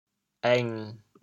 潮州 êng7 白 对应普通话: yòng ①使用，使人、物发挥其功能：采～ | 应～ | ～电 | ～拖拉机耕田 | 公～电话 | ～笔写字。
êng7.mp3